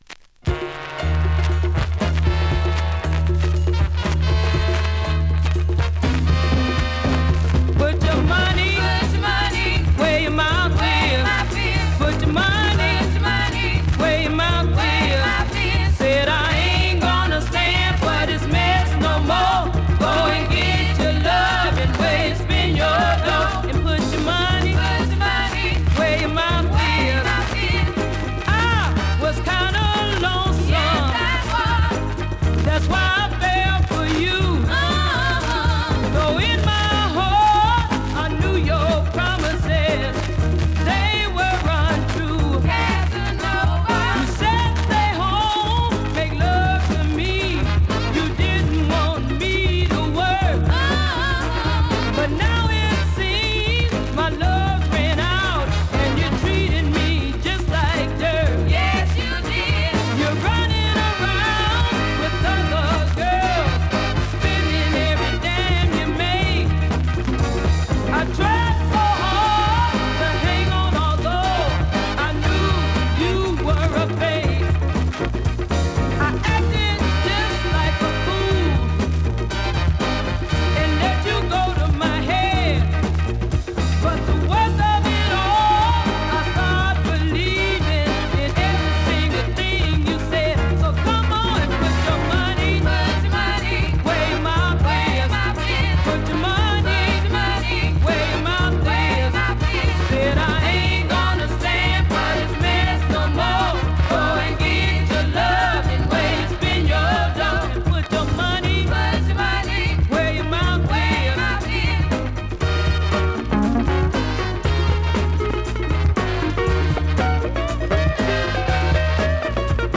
現物の試聴（両面すべて録音時間７分３４秒）できます。
3:15 シカゴ？シスター・ファンク